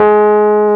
KEY RHODS 0S.wav